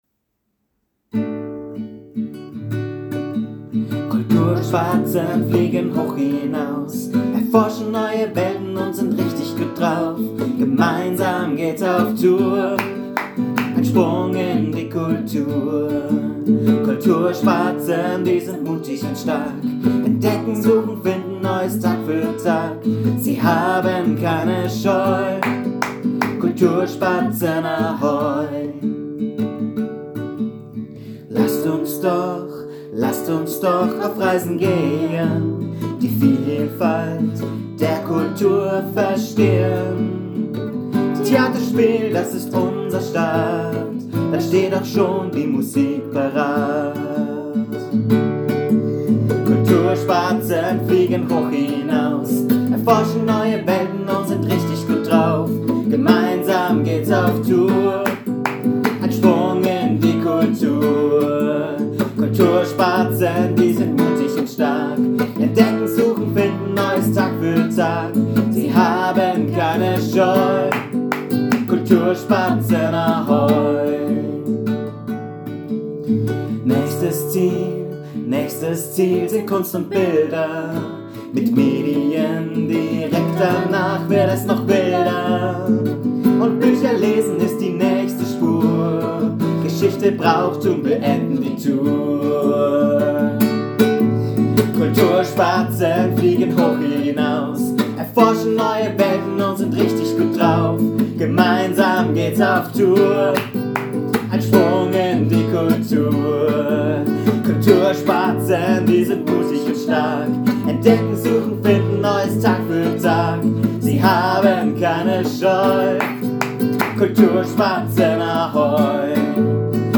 Gesang
Die professionelle Aufnahme sowie ein kurzer Mitschnitt vom Tonstudio-Besuch gibt´s direkt über die Buttons.
Geige
Gitarre
Bongos/Tamborin/Bass/Glockenspiel